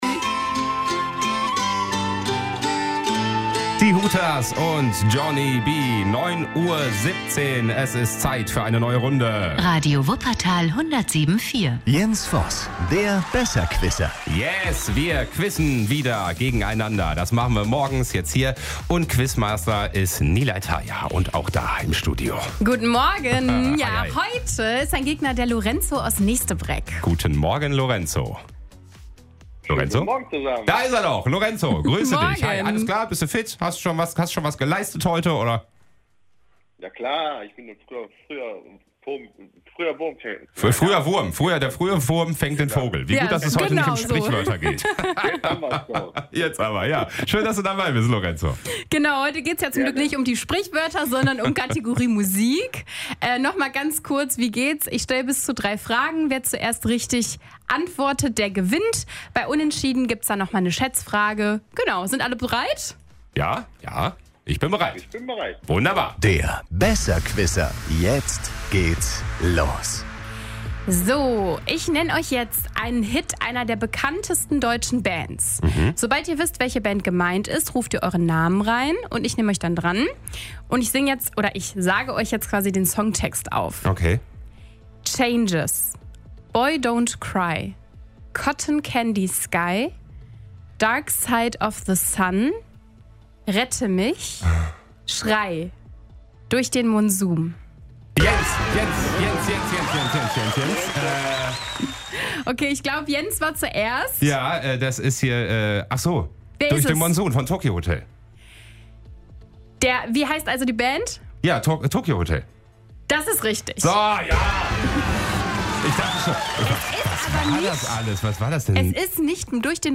Wer die Antwort weiß, ruft schnell seinen Namen. Wer zuerst richtig antwortet, holt den Punkt.